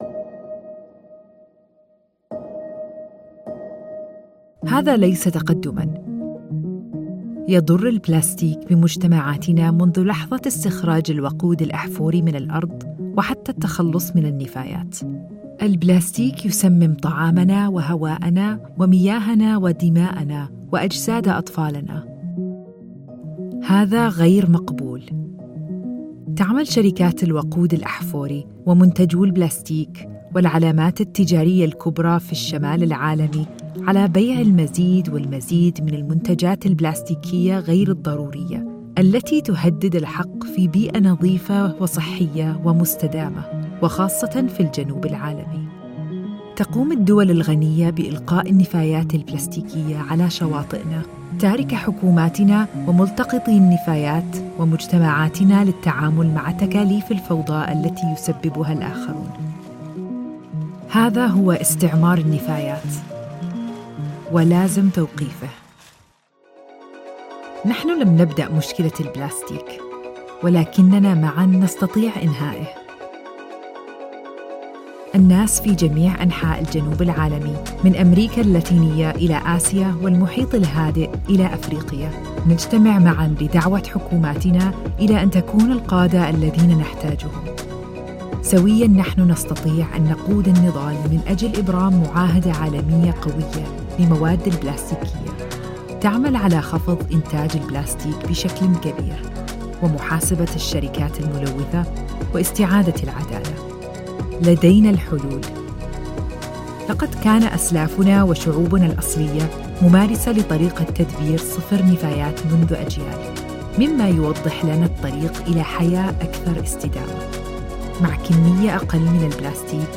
Tief, Natürlich, Unverwechselbar
E-learning